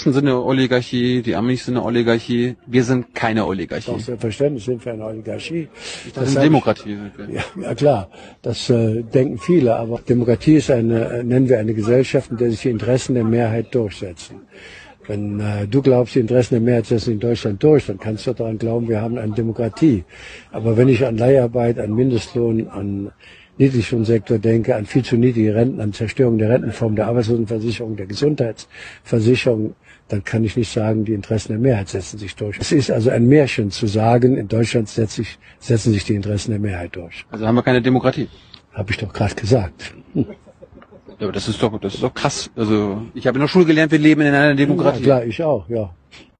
Oskar Lafontaine bringt es in diesem Interview auf den Punkt, was übrigens auch im Betrag Wir leben weder in einer Demokratie noch einer Aristokratie, sondern einer Oligarchie – Herrschaft der Wenigen vertiefter erläutert wird...